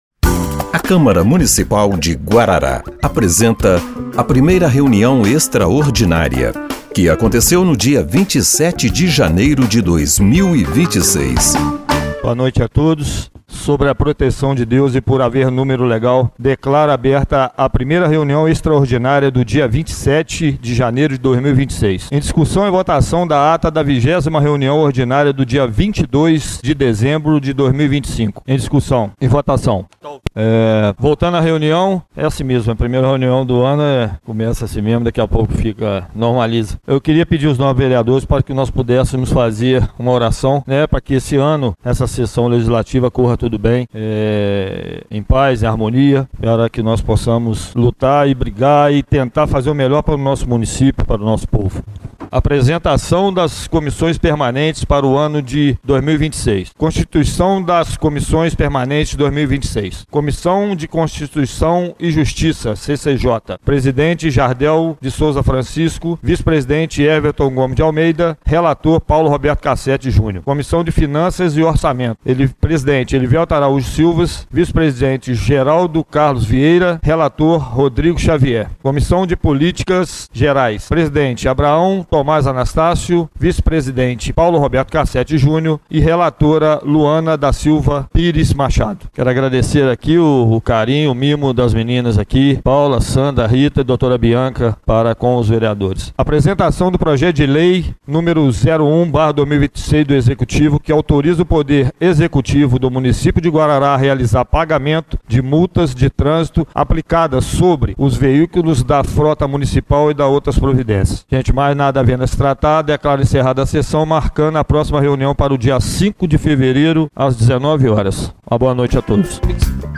1ª Reunião Extraordinária de 27/01/2026